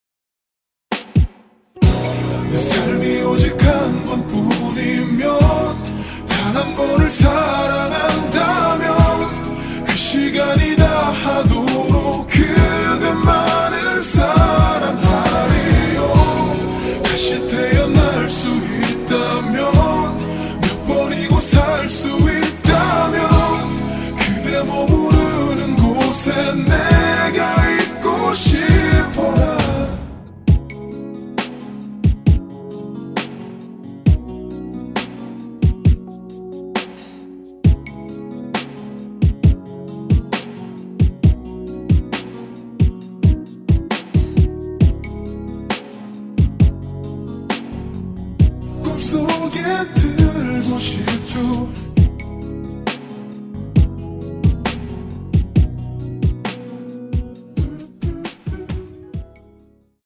코러스 MR 원키에서(-2)내린 코러스포함된 MR 입니다.
원곡의 보컬 목소리를 MR에 약하게 넣어서 제작한 MR이며